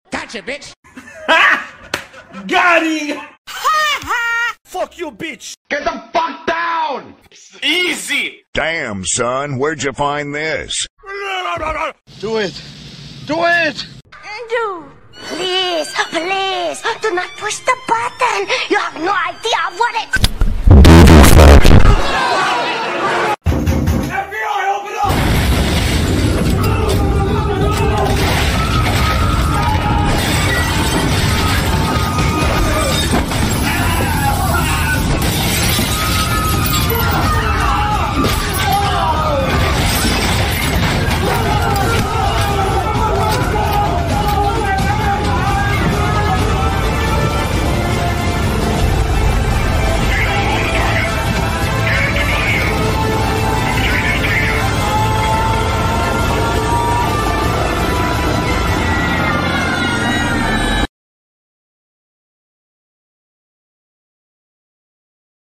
Top 10+ Sound Effects for sound effects free download
Whether you're editing for YouTube Shorts, TikTok, Instagram Reels, or long-form content, this FREE SFX pack includes meme sounds, whooshes, impacts, and more — all royalty-free and ready to use.